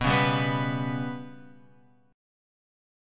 Bsus2#5 chord {7 4 5 6 x x} chord